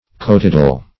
Meaning of cotidal. cotidal synonyms, pronunciation, spelling and more from Free Dictionary.
cotidal.mp3